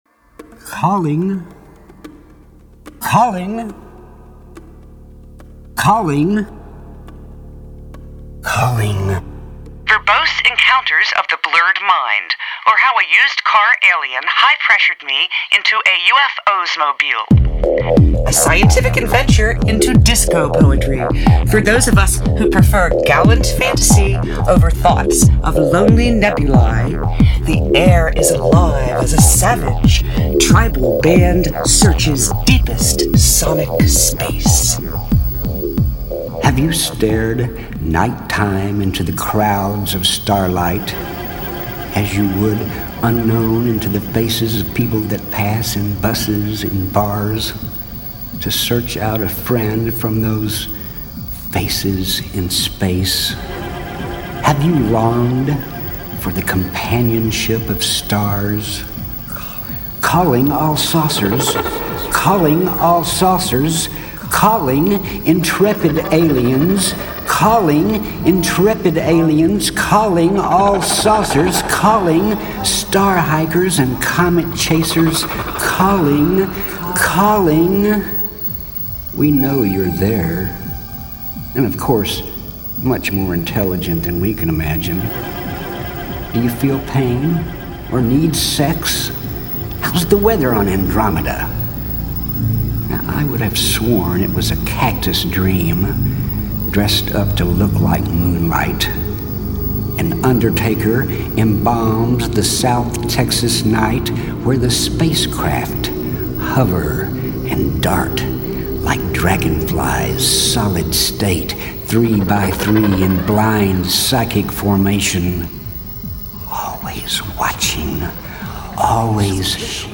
friggen grooviest thing i've heard in eons!
Funky.mp3